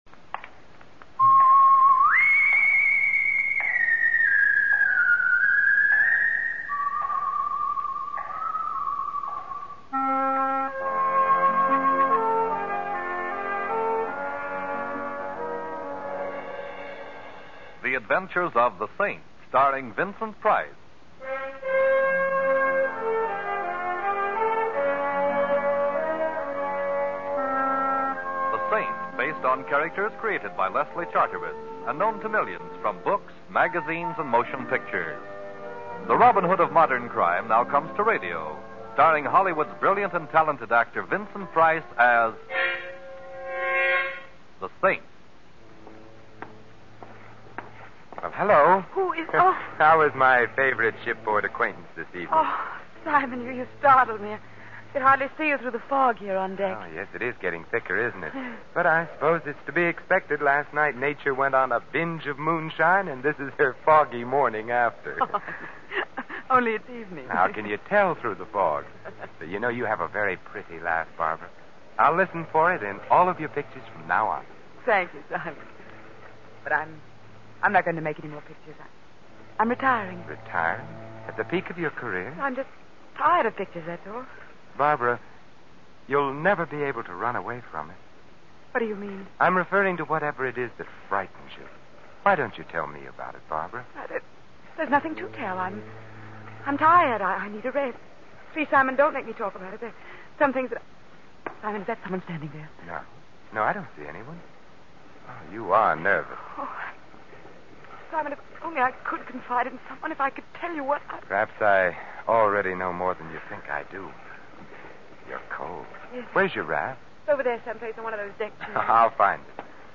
The Saint Radio Program starring Vincent Price